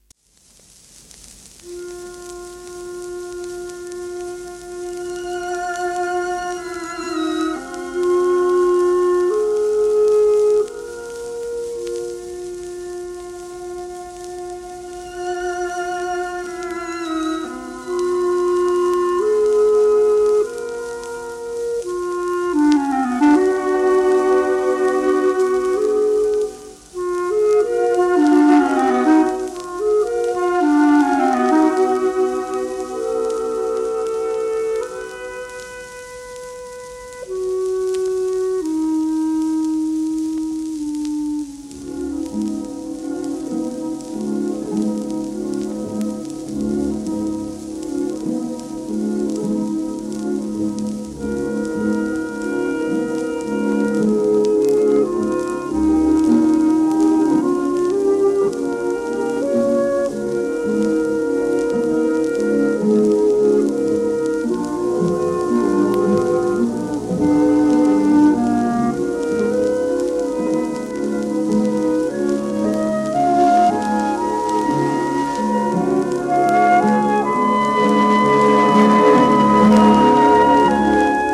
1940年録音